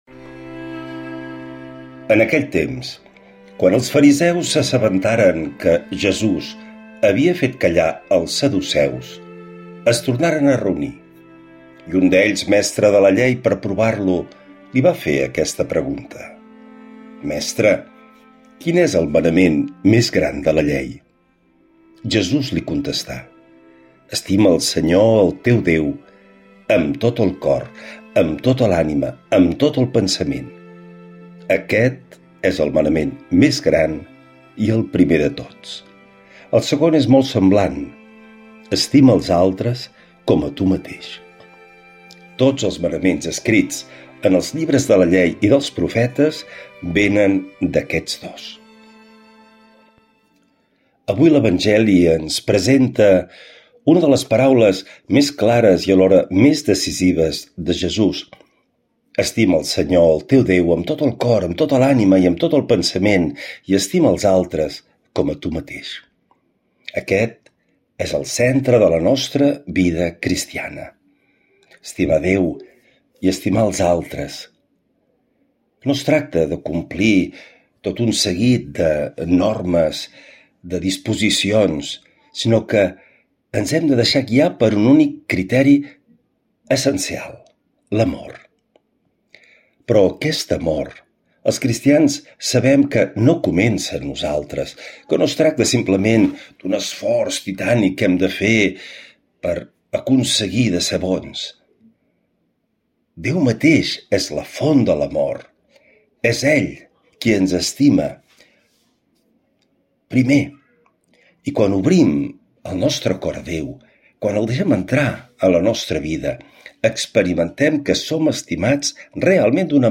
Lectura de l’evangeli segons sant Mateu